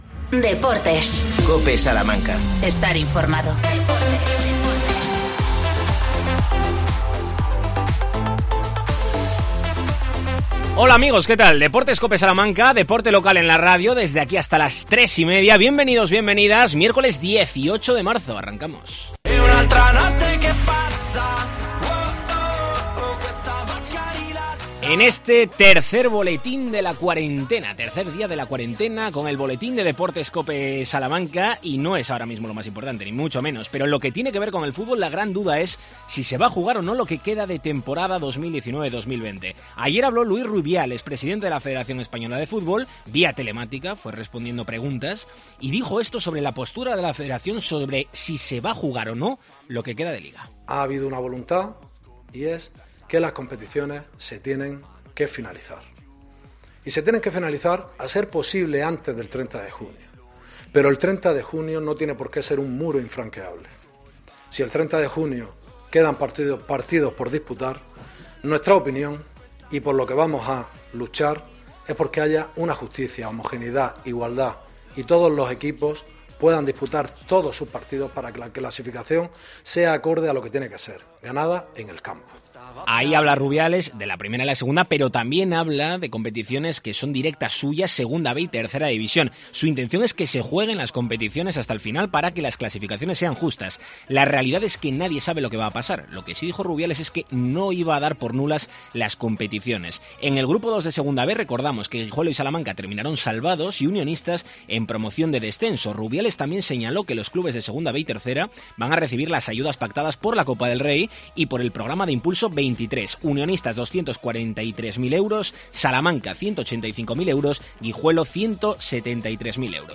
AUDIO: Boletín informativo: 18 de marzo. Deportes COPE Salamanca.